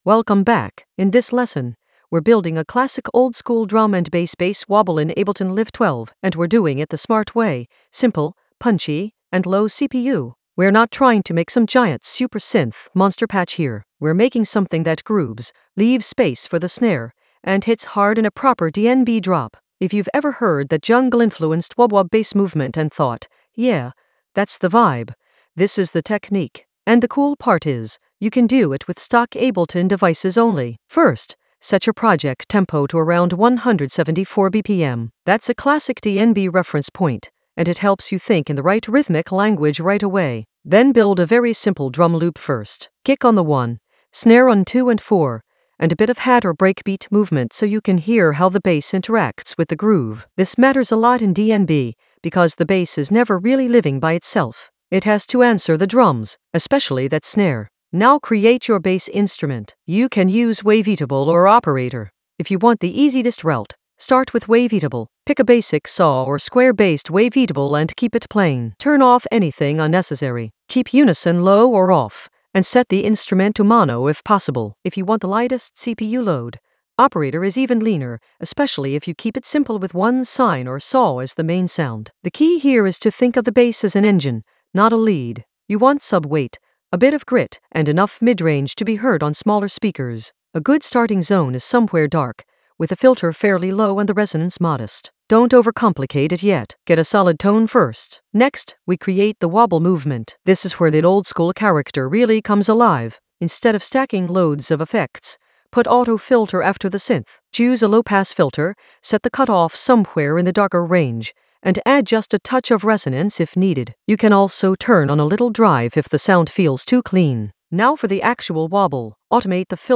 An AI-generated beginner Ableton lesson focused on Sequence oldskool DnB bass wobble with minimal CPU load in Ableton Live 12 in the FX area of drum and bass production.
Narrated lesson audio
The voice track includes the tutorial plus extra teacher commentary.